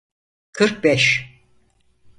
[kɯɾk bɛʃ]